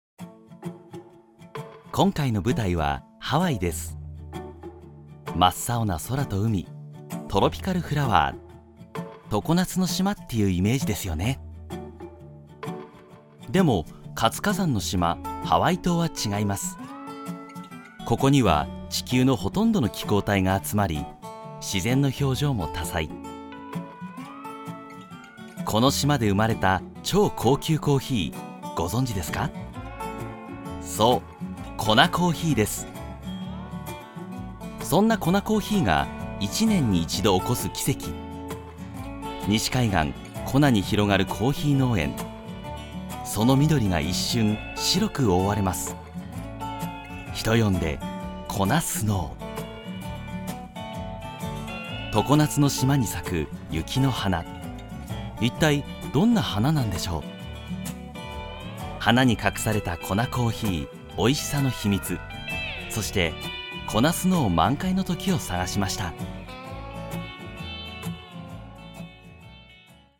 una voz de barítono enérgica y confiable
Muestras de voz nativa
Narración